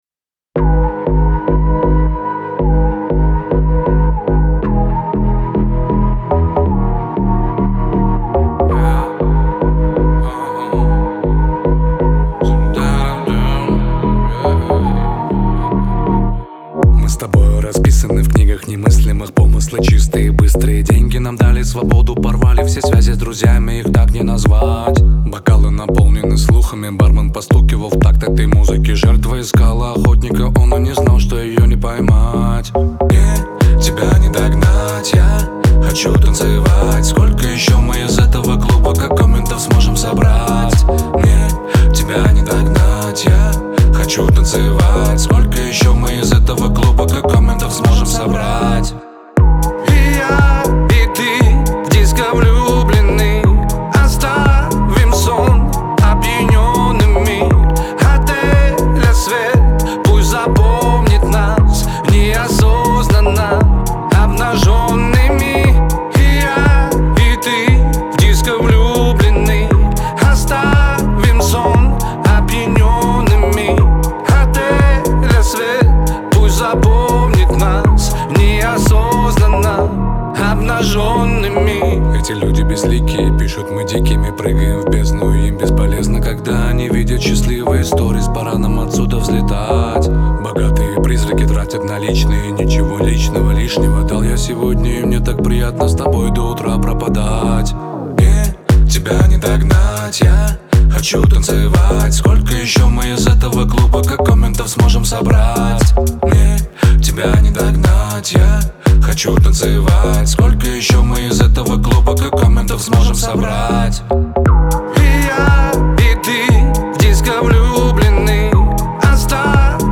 отличается заразительным вокалом и харизмой